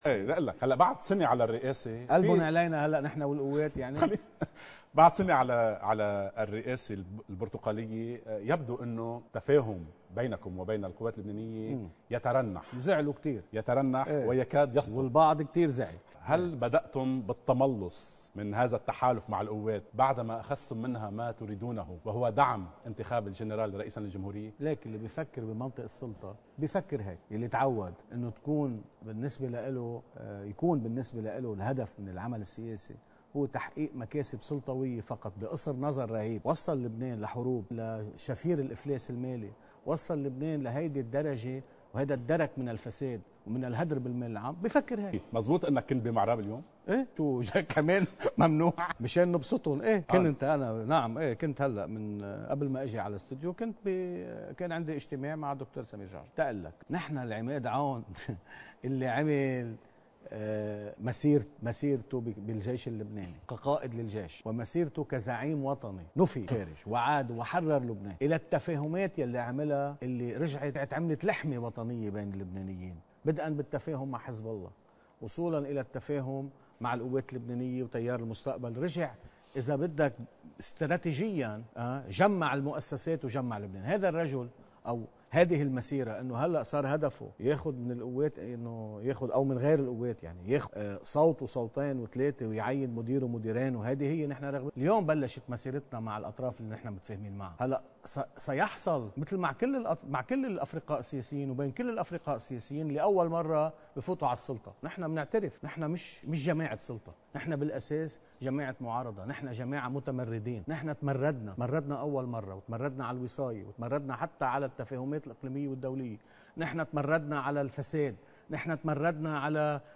مقتطف من حديث أمين سرّ تكتّل التغيير والإصلاح، رئيس مجلس المال والموازنة النائب إبراهيم كنعان: (حديث الساعة، 4 ت2 – 2017)